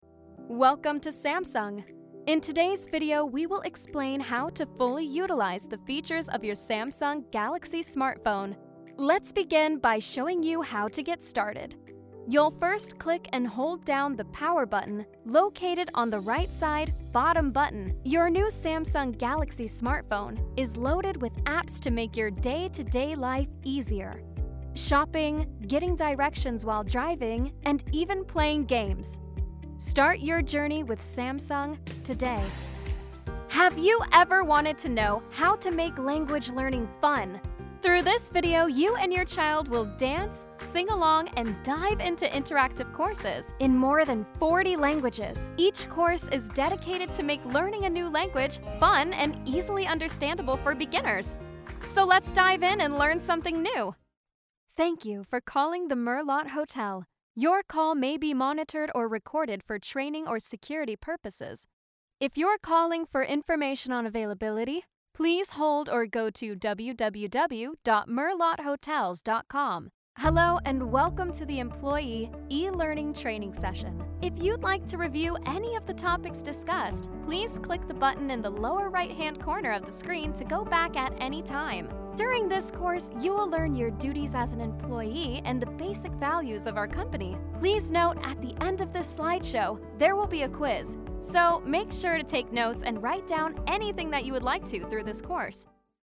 Anglais (Américain)
Commerciale, Jeune, Naturelle, Distinctive, Polyvalente
E-learning